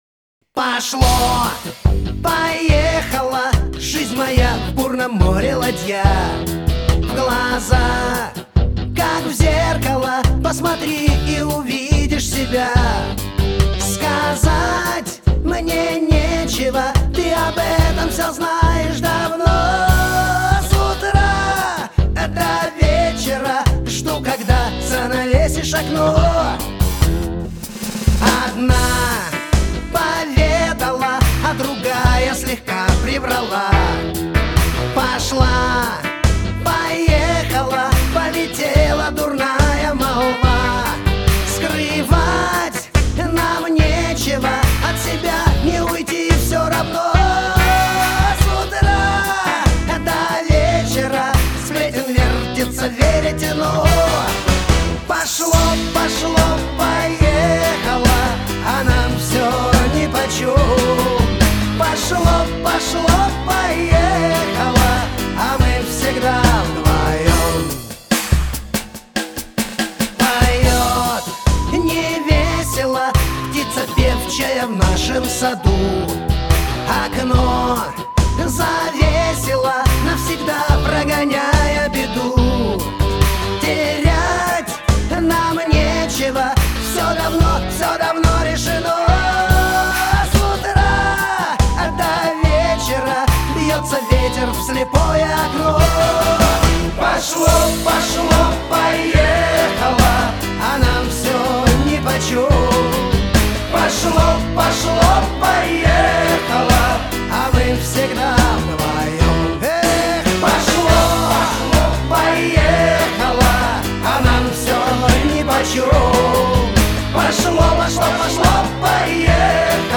В хорошем качестве.